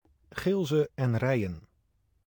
Gilze en Rijen (Dutch pronunciation: [ˌɣɪlzə ʔɛn ˈrɛiə(n)]